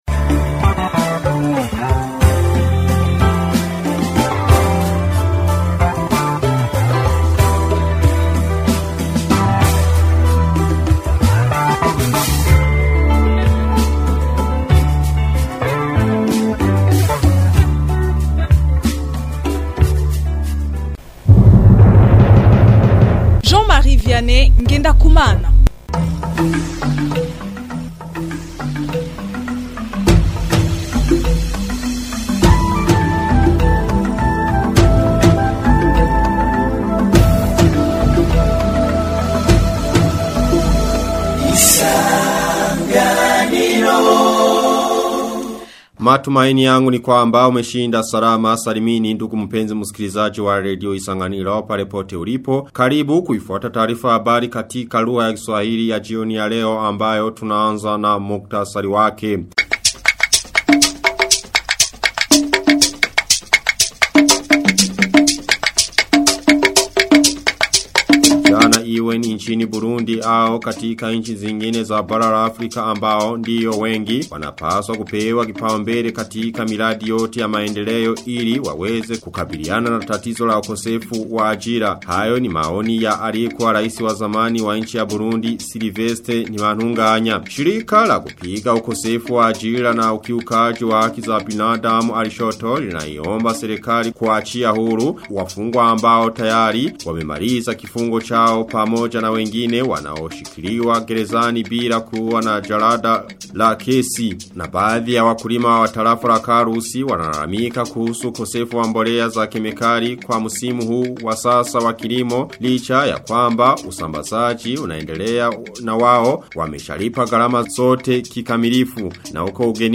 Taarifa ya habari ya tarehe 17 Februari 2026